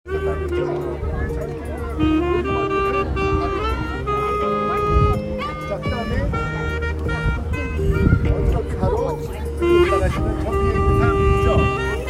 색소폰
우리를 보고 있던 거리의 악사가 내는 소리였다.